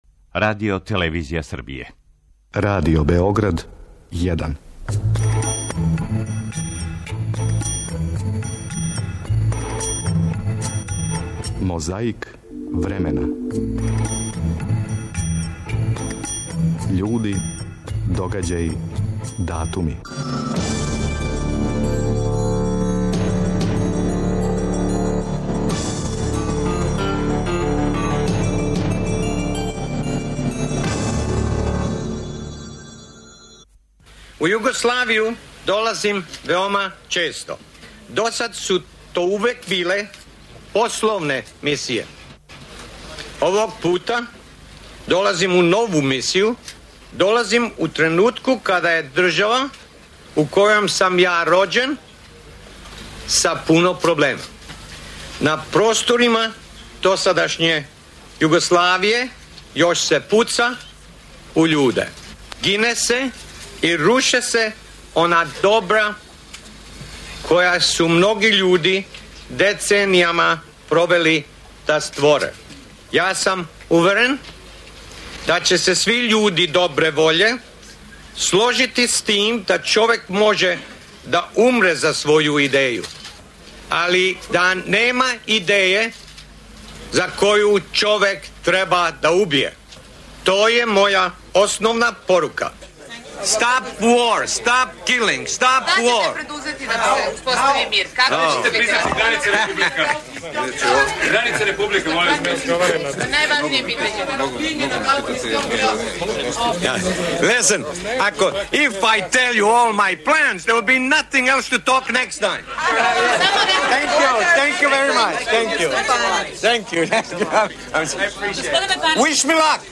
Скупу је присуствовао и друг Ђуро Пуцар Стари а било је и говора и здравица...